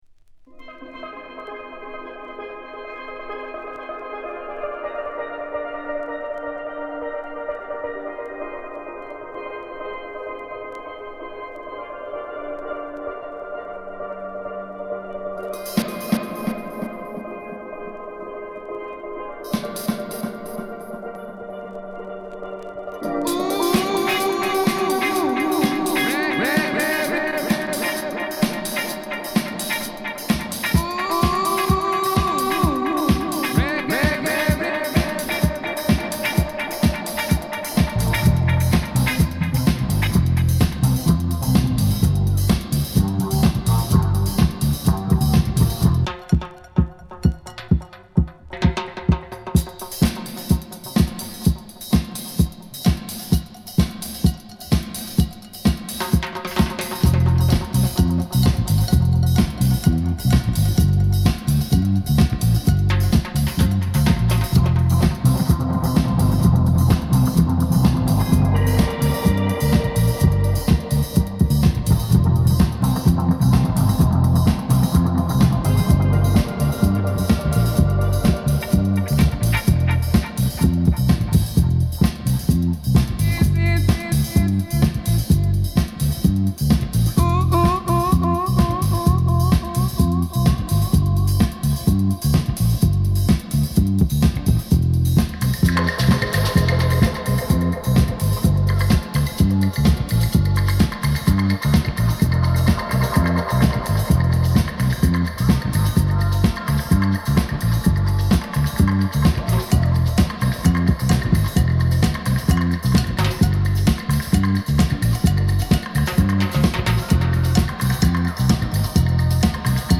うち乱れるスチールドラムやカッティングギターにたっぷりエコーを効かしたド渋ダブミックスを披露。